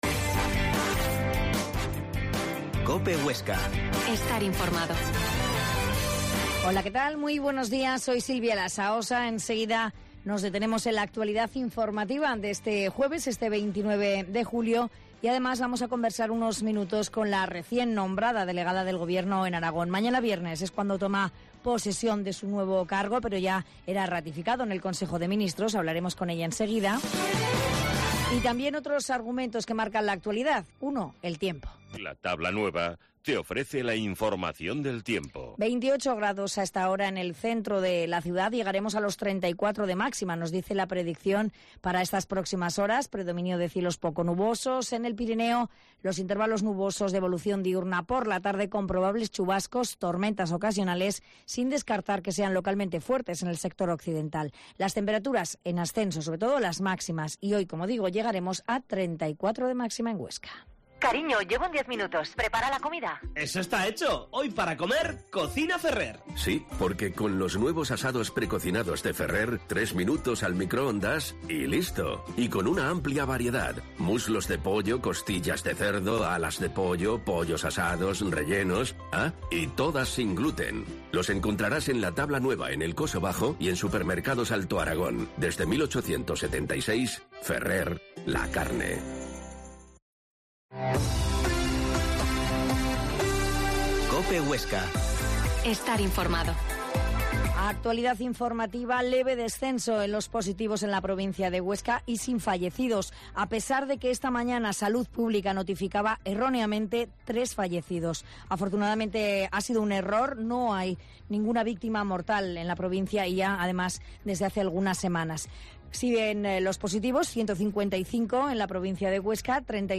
AUDIO: Herrera en COPE Huesca 12.50h. Entrevista a la nueva Delegada de Gobierno en Aragón, Rosa Serrano